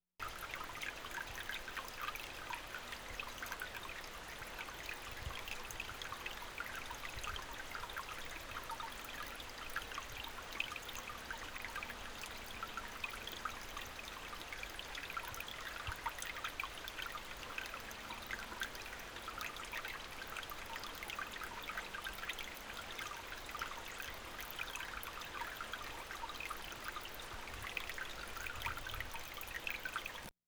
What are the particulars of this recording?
HORSESHOE BAY, SURROUNDING AREA NOV. 3, 1991 19. from 8 feet, more distant, larger stream in background